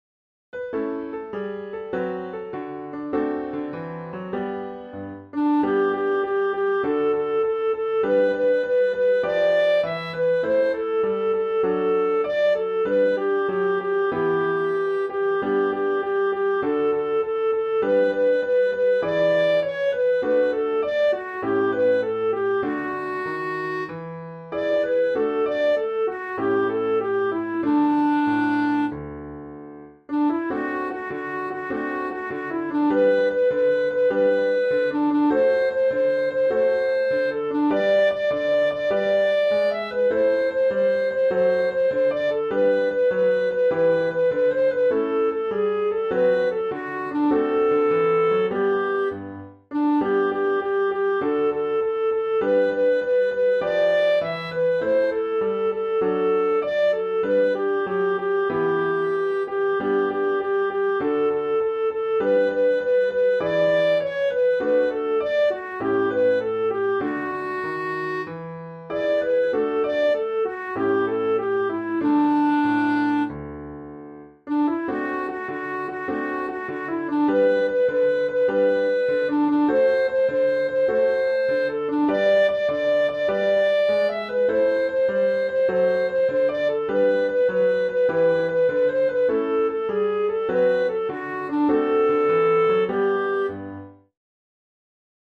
Music Hall Songs:
a straightforward arrangement for piano